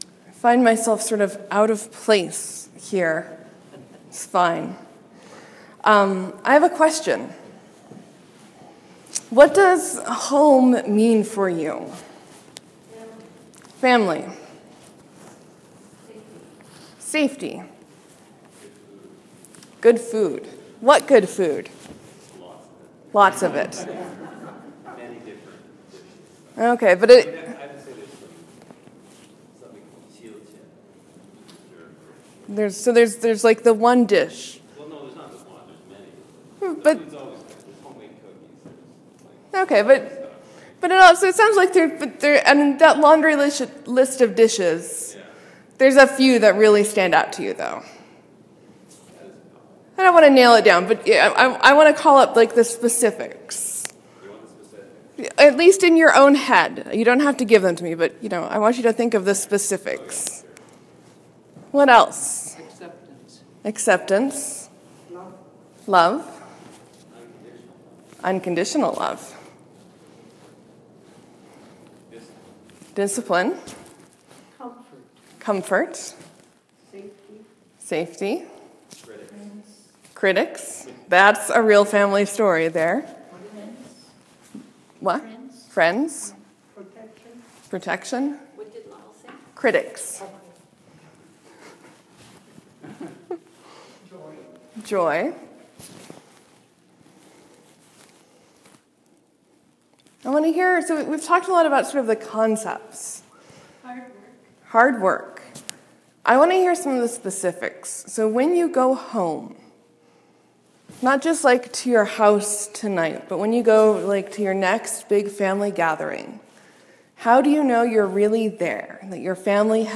Edmonton got a decent amount of snow between Friday night and Sunday morning so our worship was a bit more intimate than normal. Instead of our usual Morsels & Stories we started with a really interactive section. I did my best to make sure the gist of the conversation got caught by the mic.
Sermon: Jesus has deep passion for God’s home. What does home mean to us?